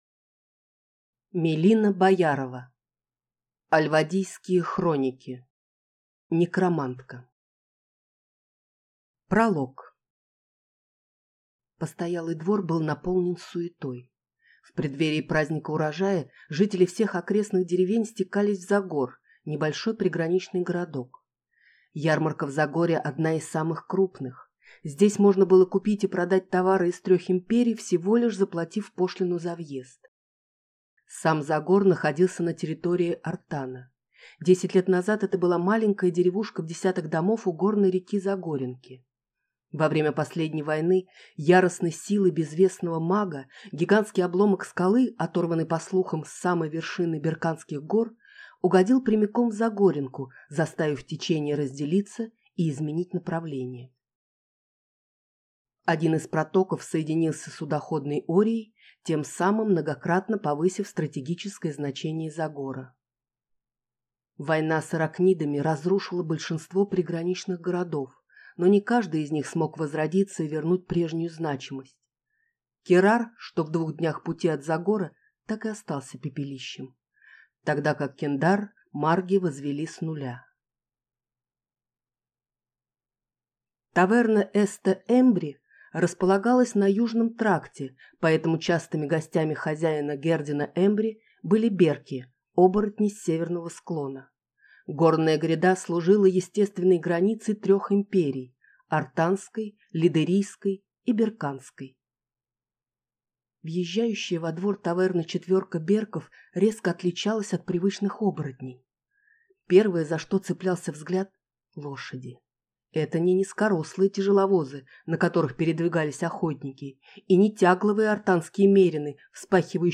Аудиокнига Некромантка | Библиотека аудиокниг